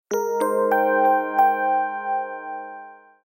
Genel olarak telefonun güçlü özelliklerini yansıtan polifonik ve güçlü tonların bulunduğu bildirim seslerinin firmanın yıllardır süregelen seslerin modern yorumlarını içermektedir.